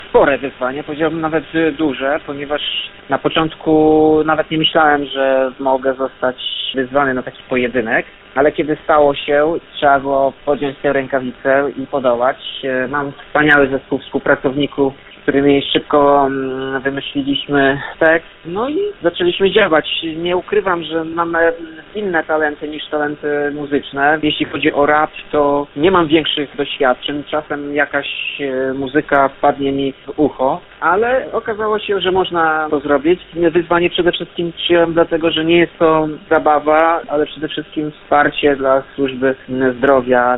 Jak przyznaje w rozmowie z Radiem 5 prezydent Andrukiewicz – rapowanie było dla niego nowością.